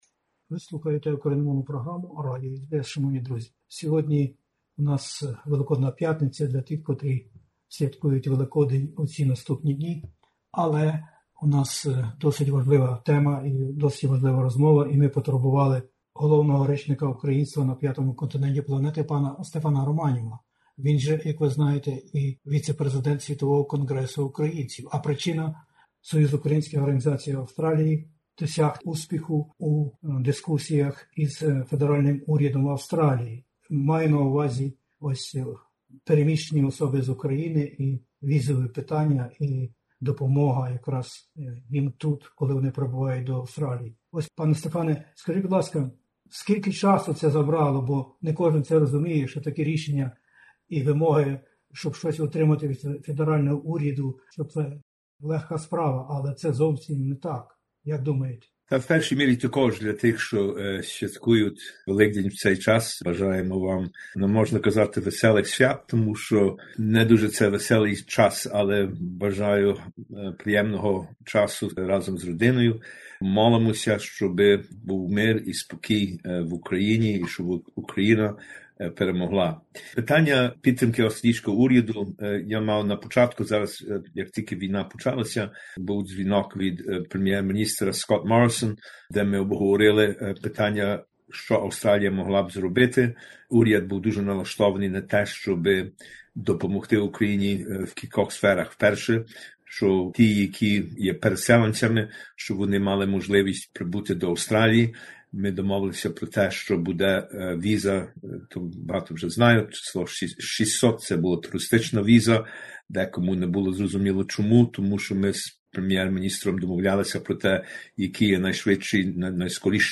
Зокрема в інтерв'ю: допомога Україні та українцям через різні допомогові фонди, постійний діалог із Федеральним урядом Австралії та Федеральною опозицією, міґраційні питання, візовий режим, співпраця з усіма урядами штатів Австралії та українськими громадами на місцях, зі Світовим Конґресом Українців.